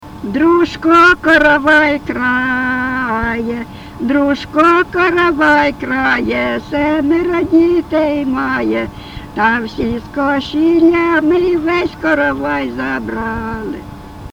ЖанрВесільні
Місце записус. Привілля, Словʼянський (Краматорський) район, Донецька обл., Україна, Слобожанщина